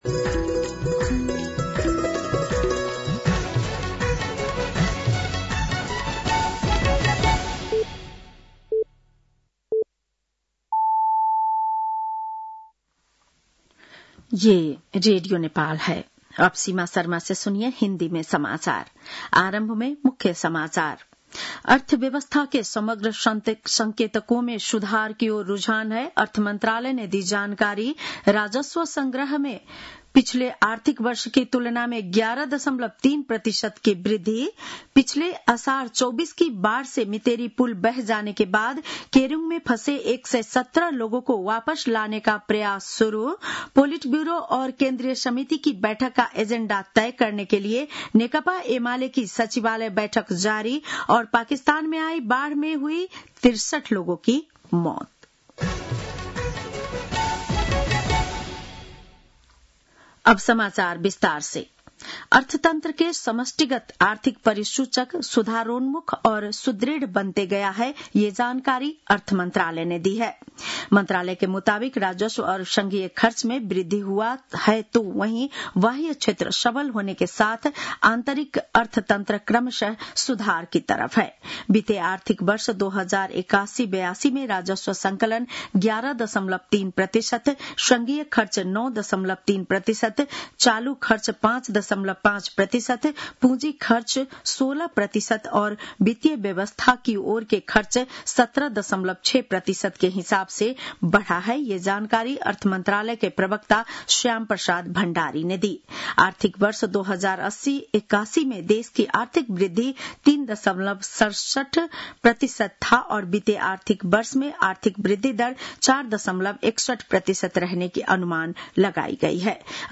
बेलुकी १० बजेको हिन्दी समाचार : १ साउन , २०८२
10-PM-Hindi-NEWS-4-1.mp3